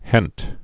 (hĕnt)